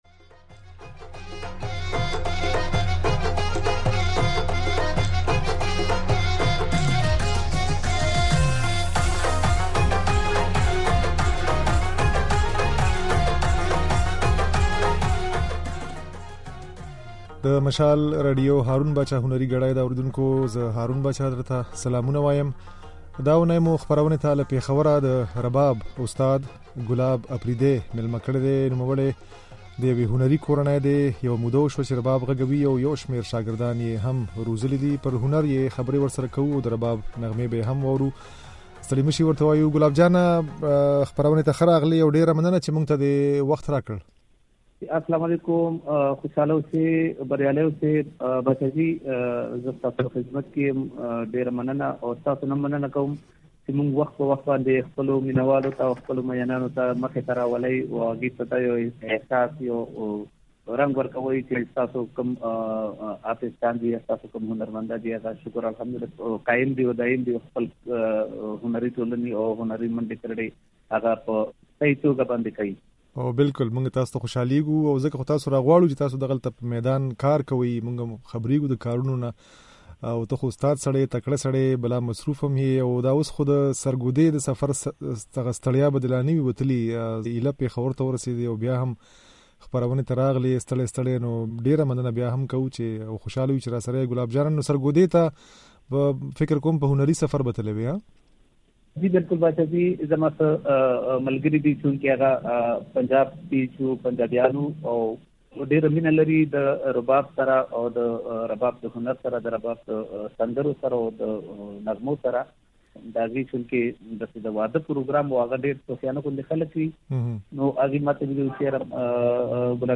د نوموړي خبرې او په رباب غږولې ځينې نغمې يې د غږ په ځای کې اورېدای شئ.